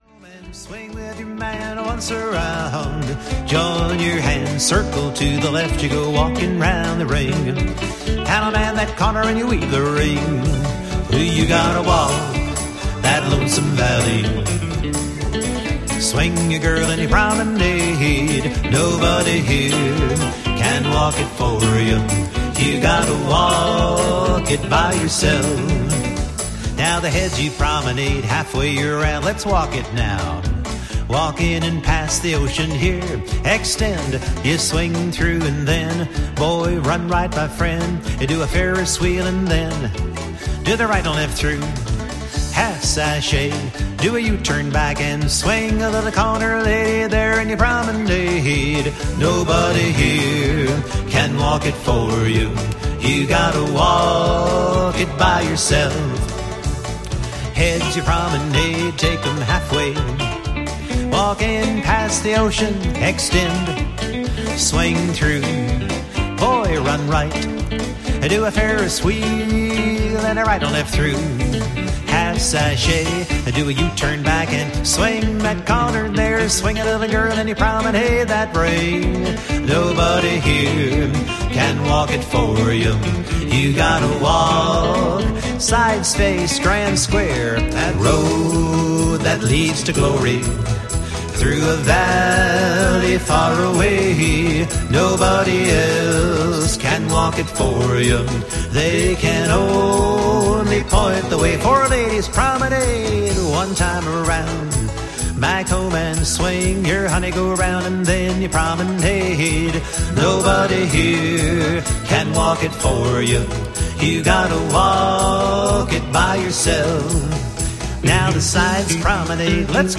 Singing Calls Brand